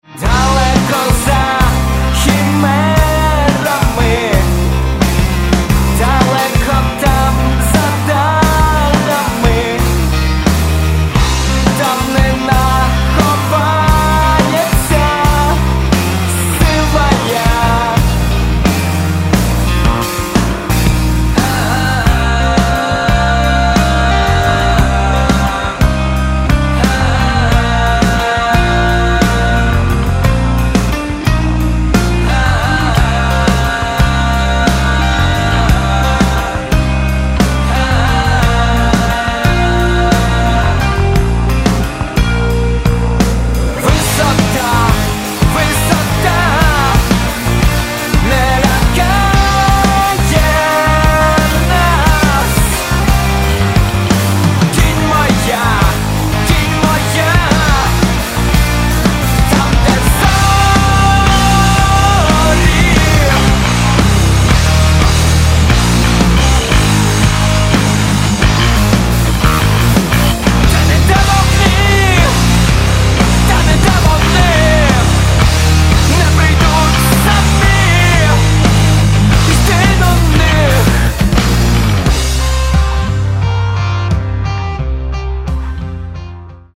Catalogue -> Rock & Alternative -> Energy Rock